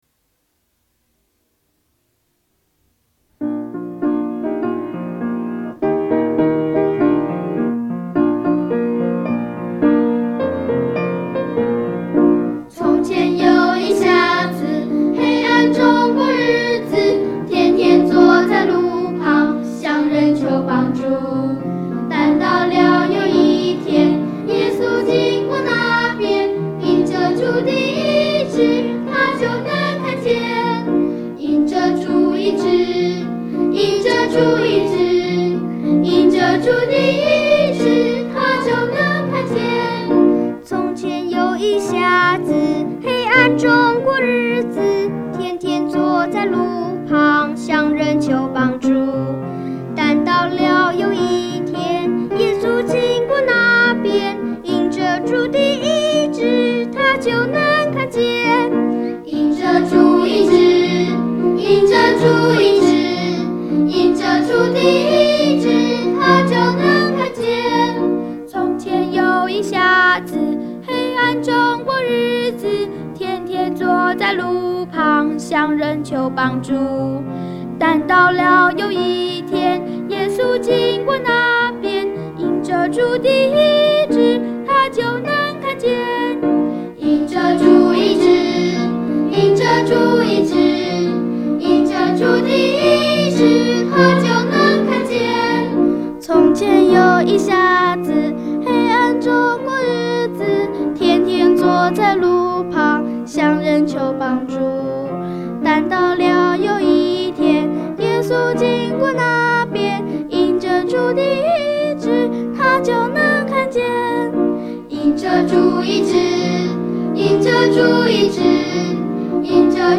儿歌——《从前有一瞎子》